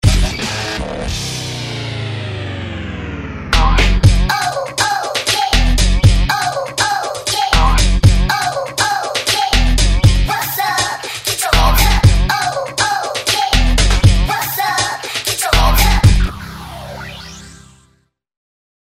Moderato [90-100] amour - voix - danse - hip hop - jeune